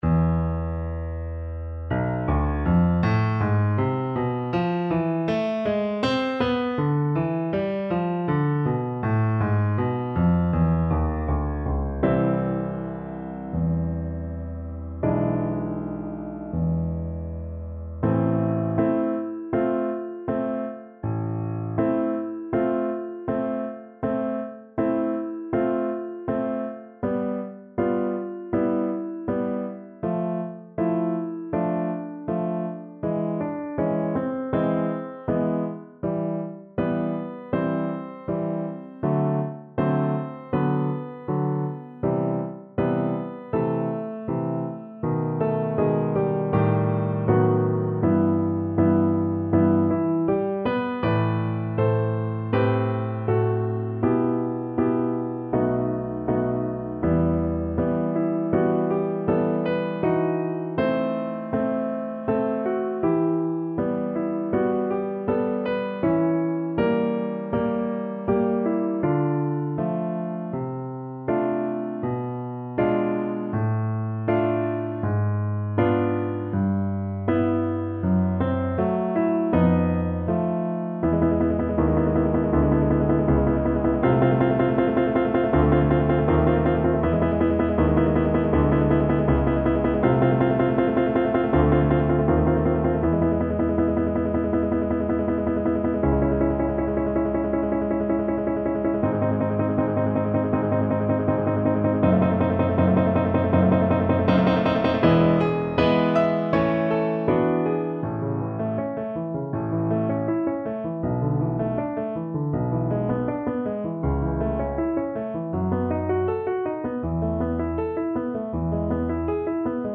Classical (View more Classical Violin Music)